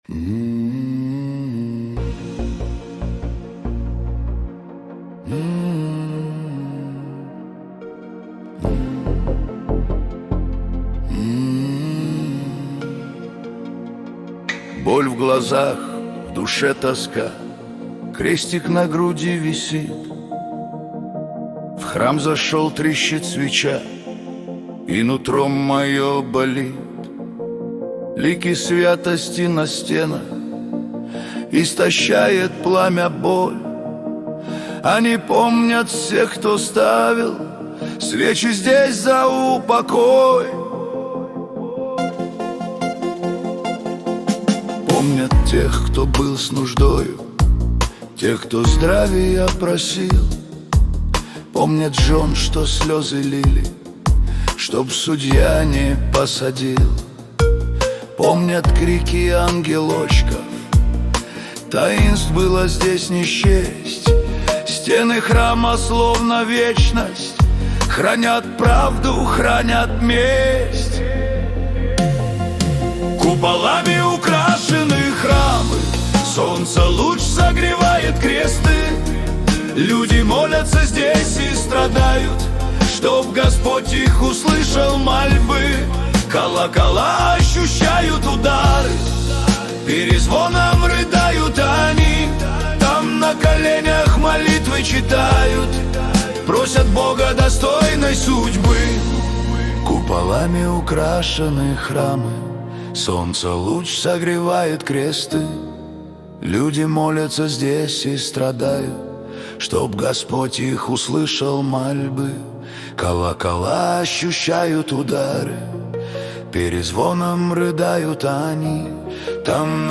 Качество: 229 kbps, stereo
Поп музыка